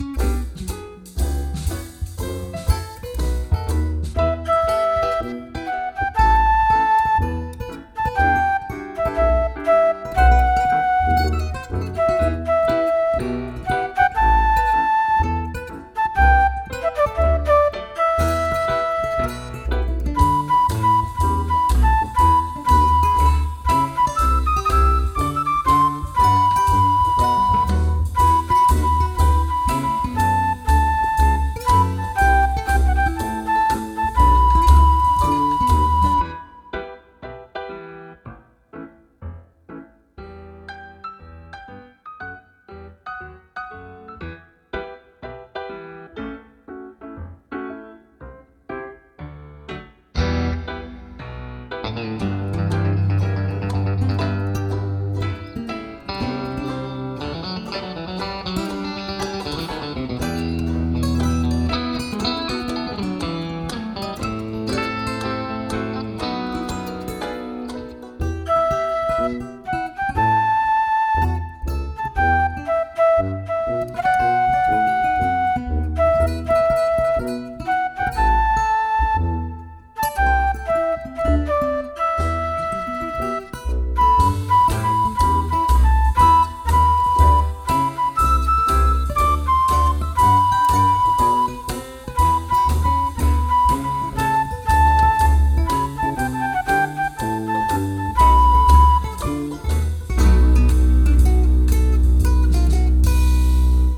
Deux versions instrumentales